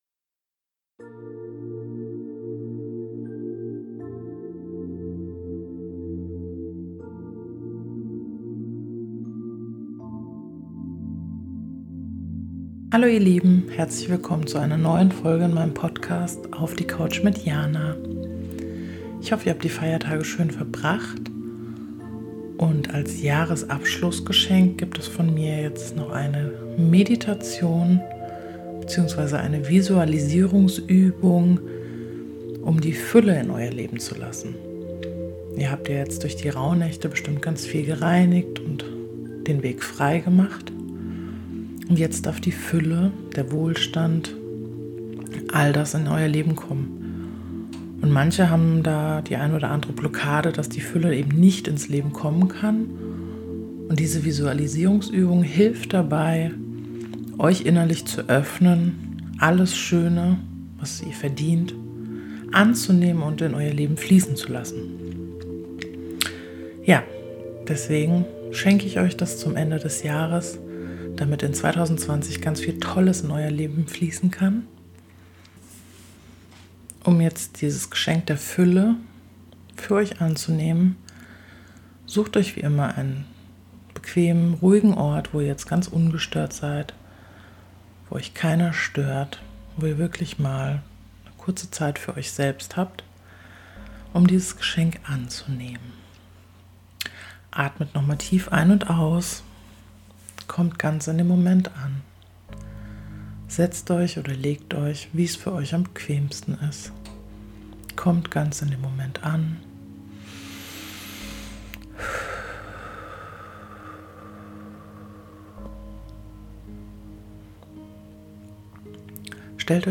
Heute schenke ich euch eine Meditation zur Fülle.